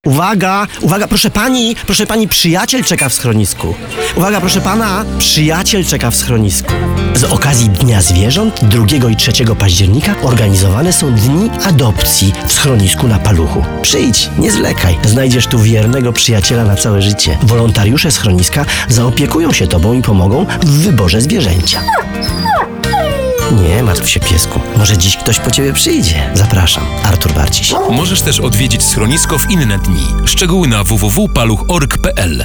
Wzięliśmy też udział w produkcji spotu dźwiękowego promującego adopcję ze schronisk, który był emitowany w polskim radiu.
spot-Przyjaciel-czeka-w-schronisku.wav